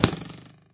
bowhit1.ogg